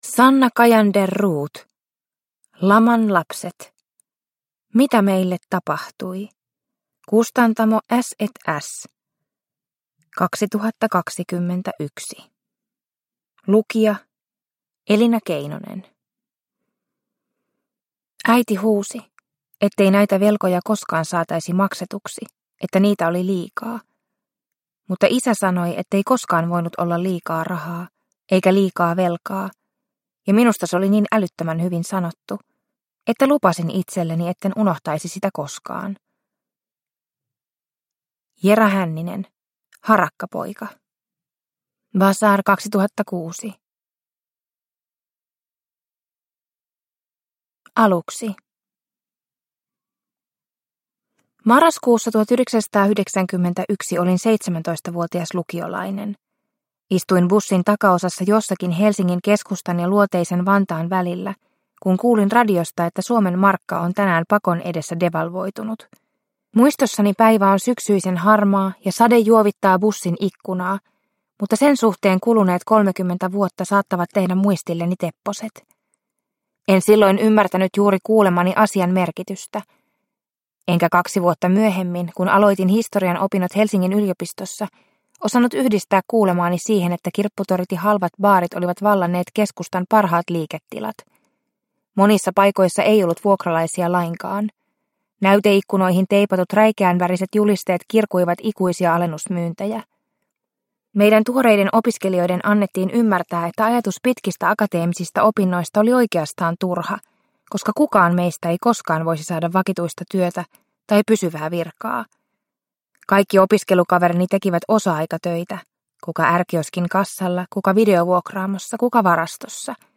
Laman lapset – Ljudbok – Laddas ner